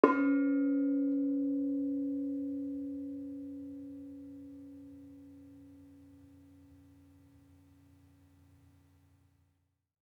Bonang-C#3-f.wav